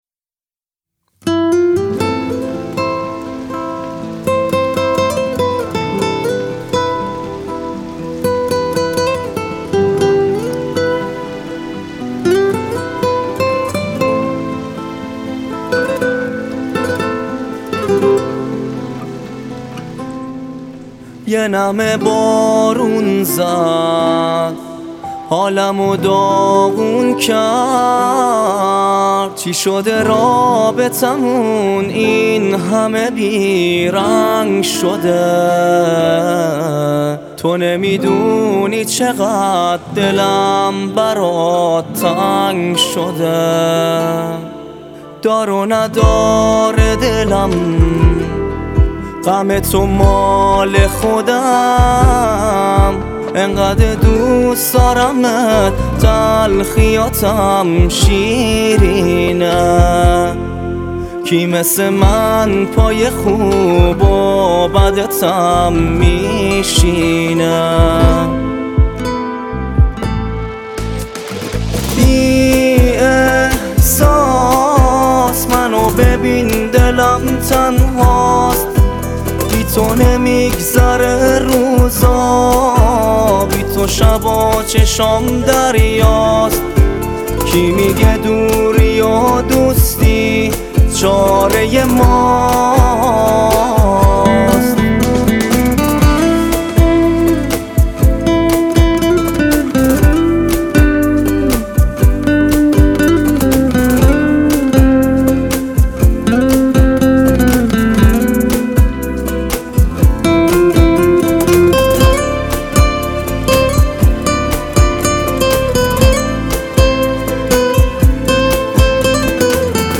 ژانر: پاپ
موزیک احساسی و قشنگ